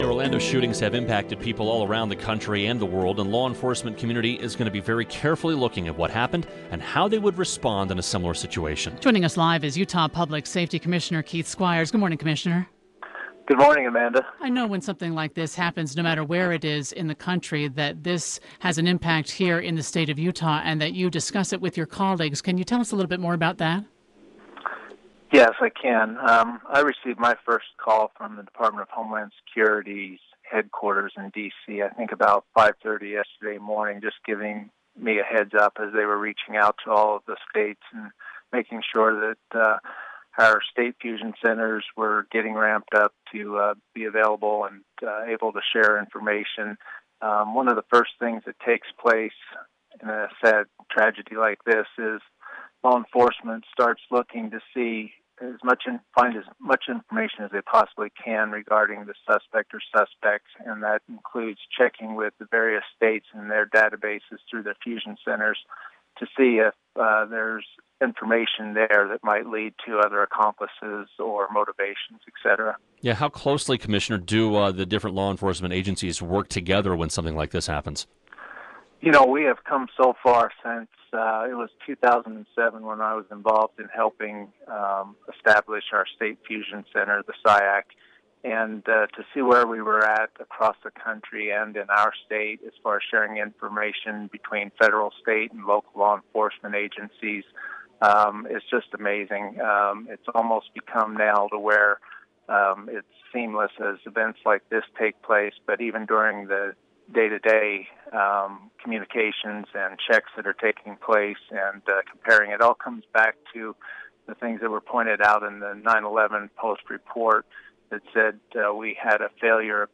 Commissioner Squires spoke this morning with Utah's Morning News. photo courtesy Utah Department of Public Safety.